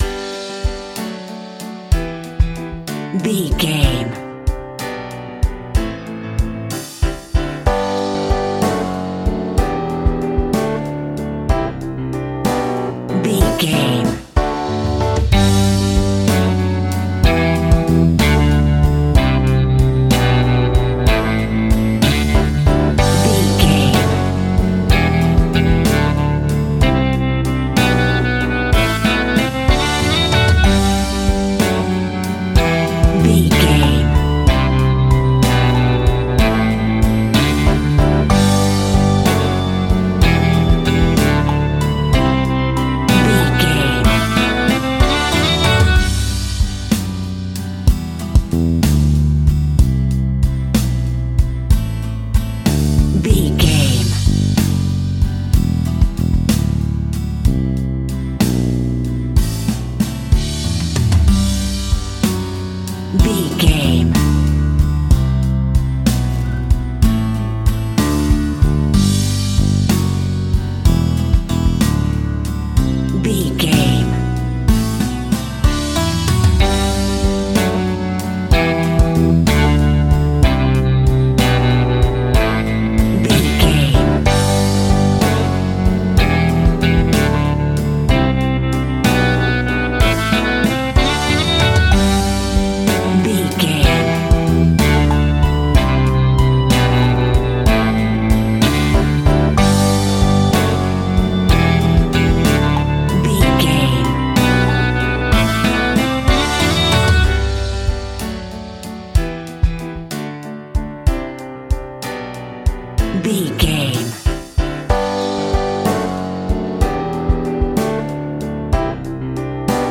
Uplifting
Ionian/Major
A♭
pop rock
indie pop
fun
energetic
acoustic guitars
drums
bass guitar
electric guitar
piano
electric piano
organ